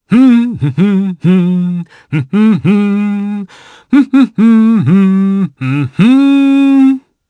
Roman-Vox_Hum_jp.wav